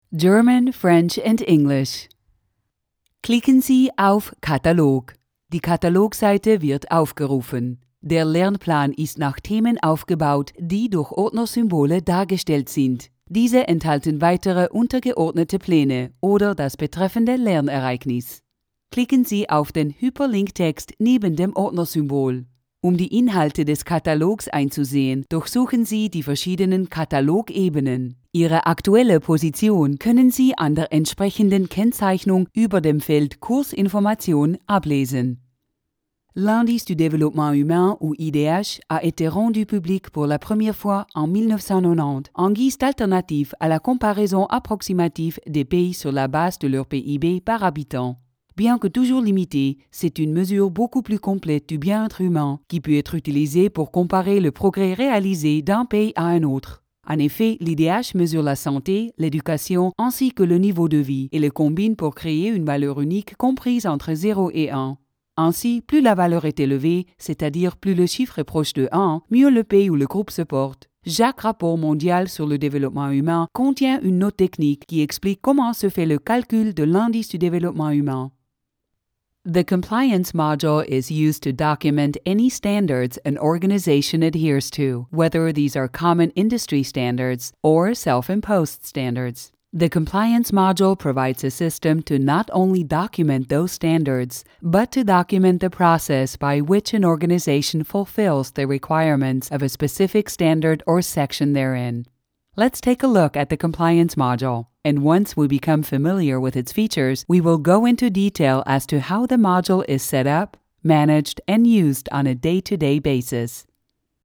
freundlich, ehrlich, authentisch, sinnlich, warm, variabel, seriös, sympatisch, wandlungsfähig, emotional
Sprechprobe: eLearning (Muttersprache):
conversational, friendly, real, soothing, educational, informative, warm